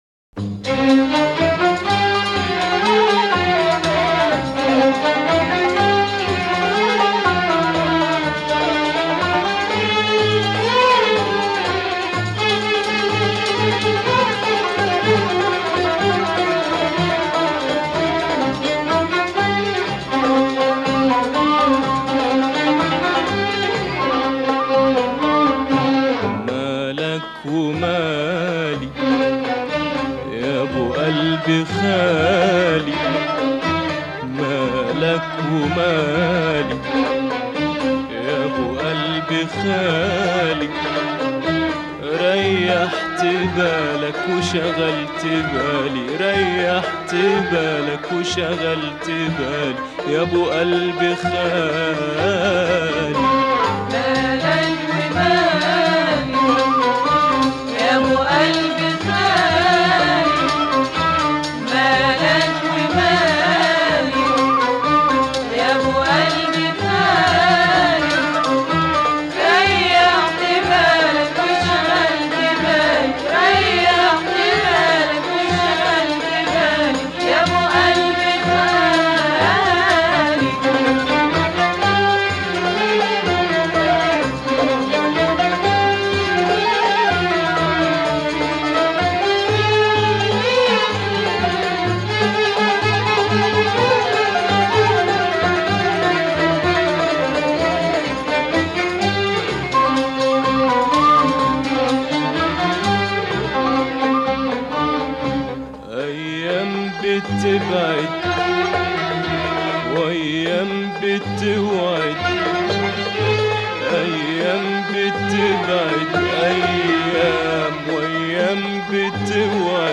ترانه عربی اغنية عربية